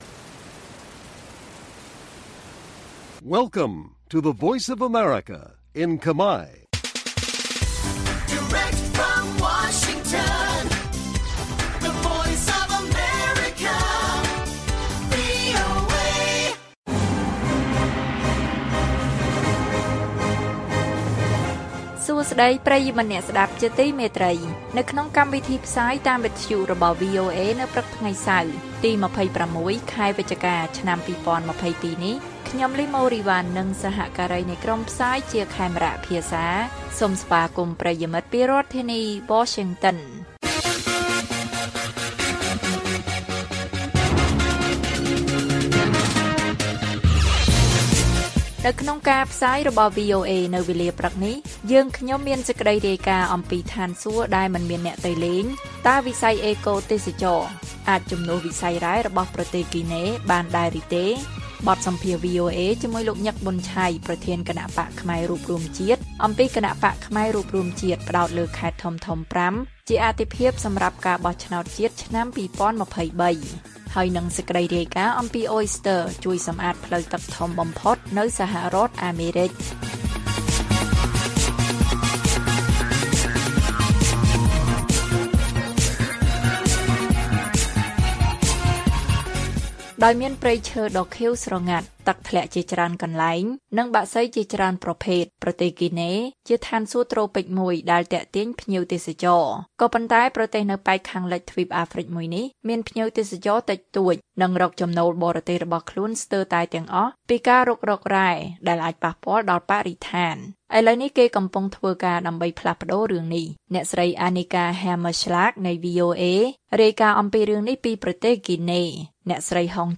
ព័ត៌មានពេលព្រឹក ២៦ វិច្ឆិកា៖ តើវិស័យអេកូទេសចរណ៍អាចជំនួសវិស័យរ៉ែរបស់ប្រទេសហ្គីណេបានទេ?